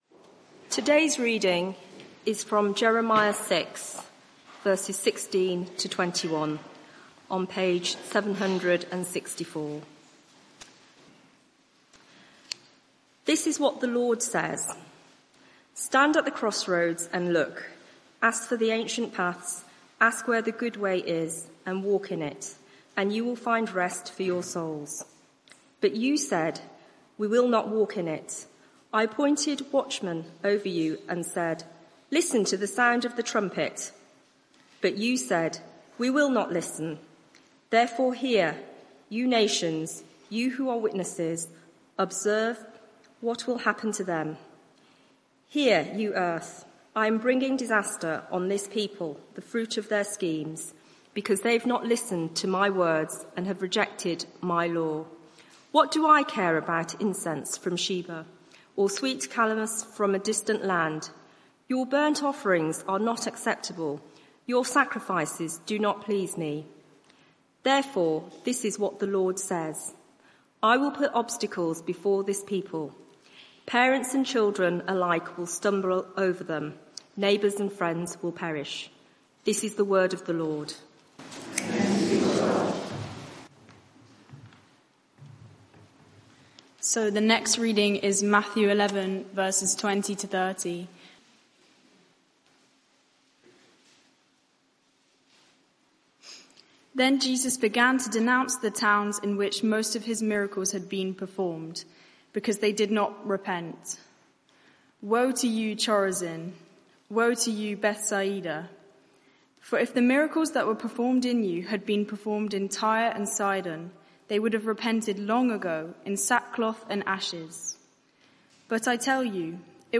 Media for 6:30pm Service on Sun 13th Jul 2025 18:30 Speaker
Sermon (audio) Search the media library There are recordings here going back several years.